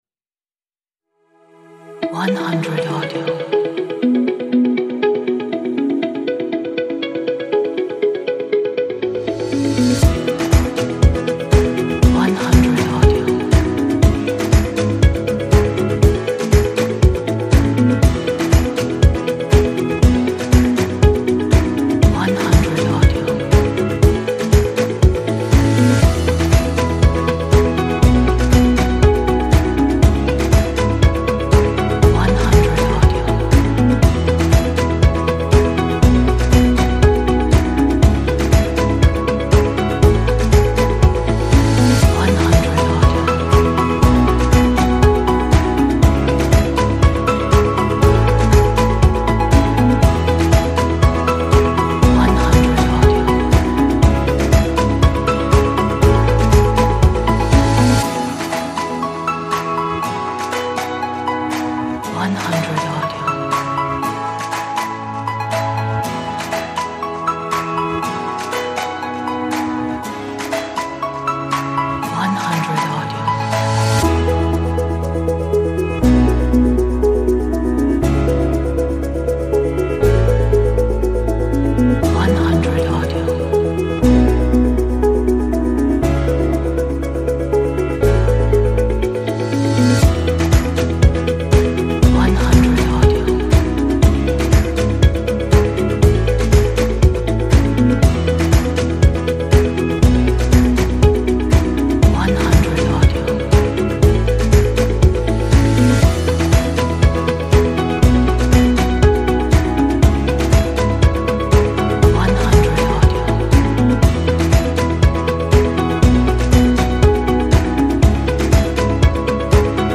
鼓舞人心的背景音乐，充满活力和快乐的心情。